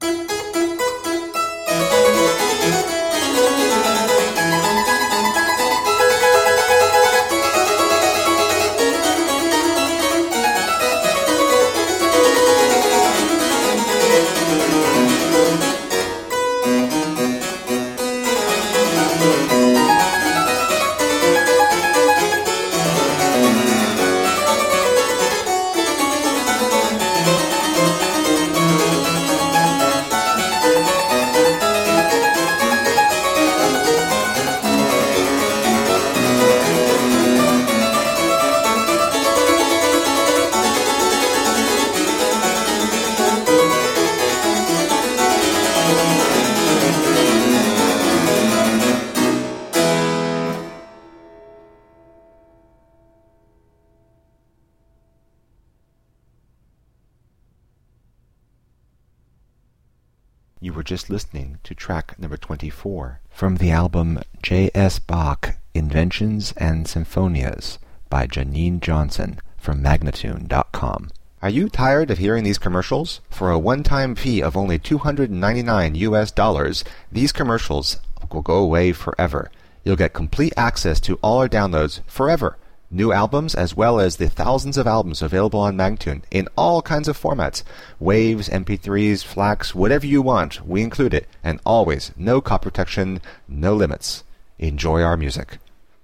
Classical, Baroque, Instrumental
Harpsichord